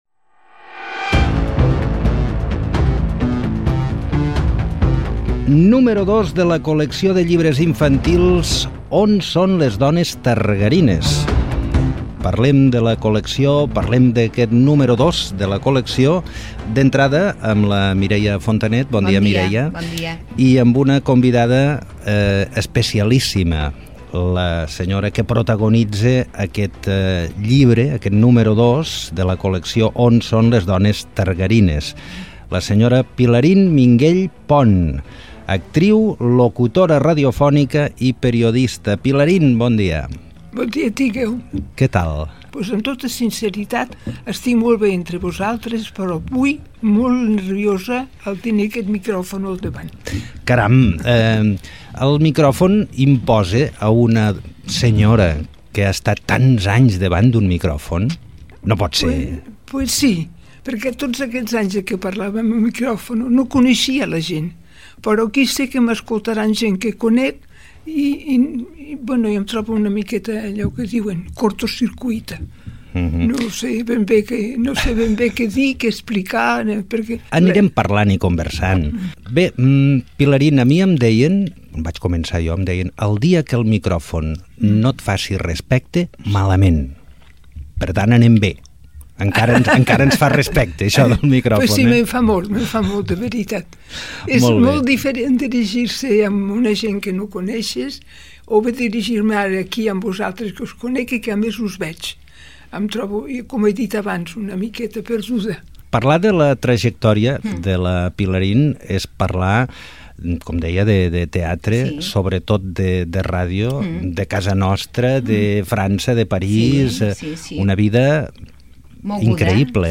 Entrevista
FM